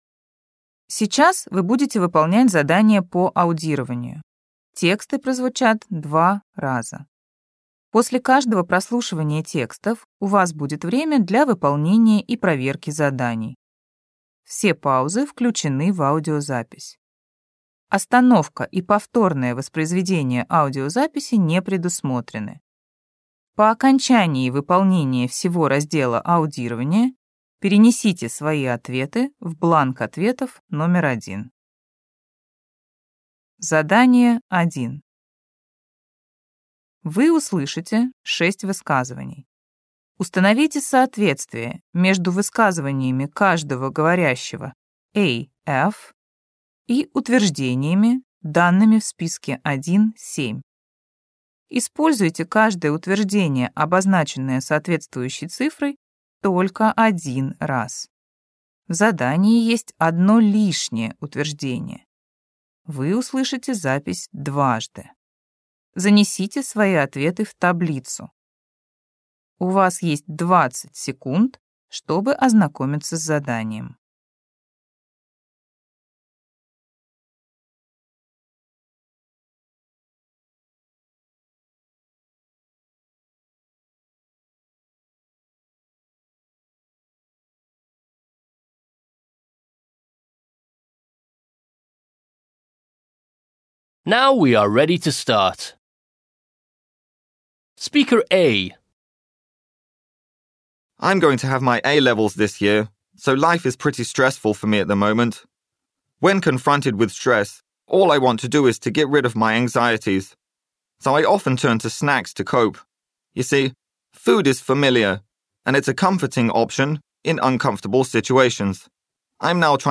Раздел 1. Аудирование Вы услышите 6 высказываний.
Вы услышите диалог.